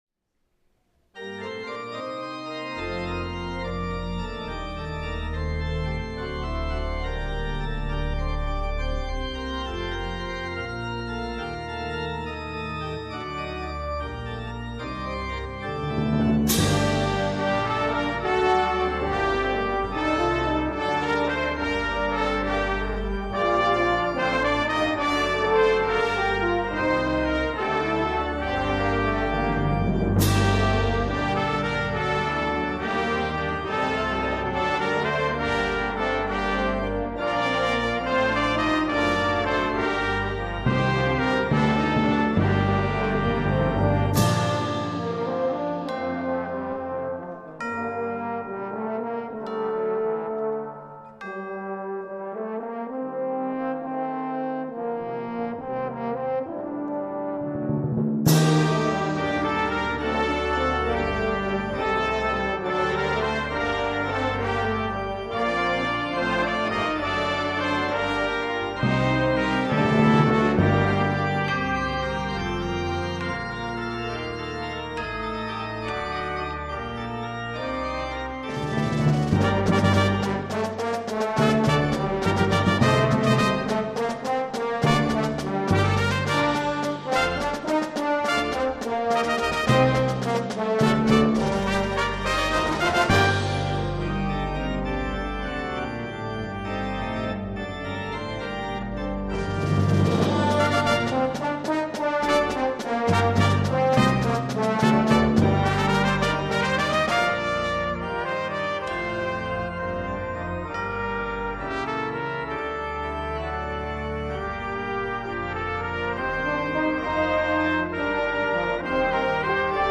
Good Friday Communion Service